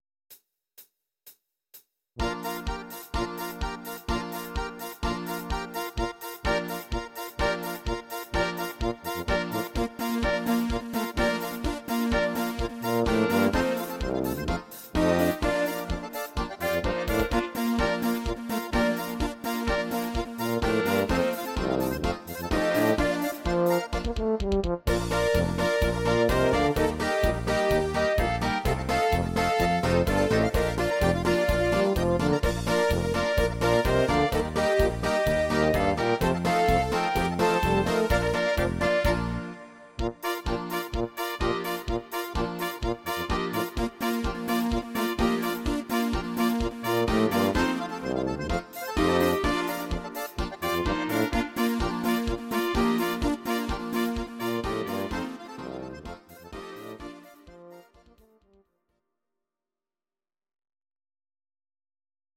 These are MP3 versions of our MIDI file catalogue.
Please note: no vocals and no karaoke included.
Oberkrainer Sound